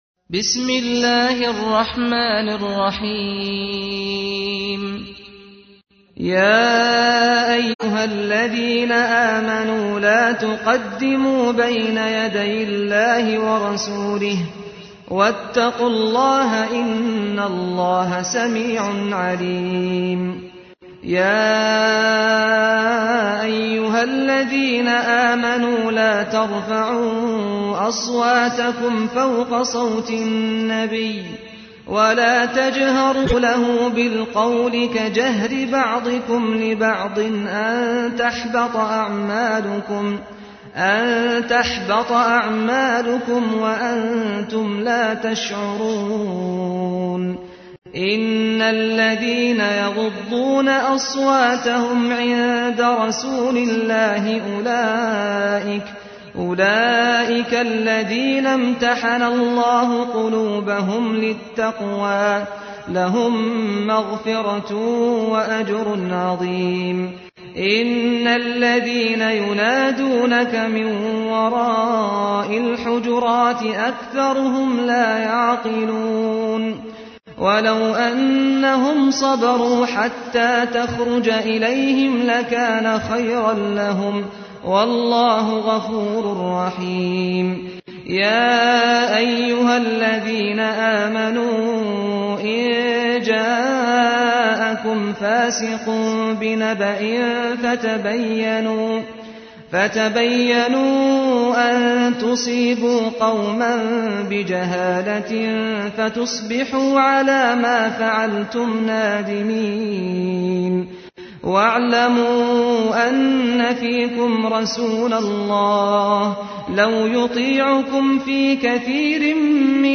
تحميل : 49. سورة الحجرات / القارئ سعد الغامدي / القرآن الكريم / موقع يا حسين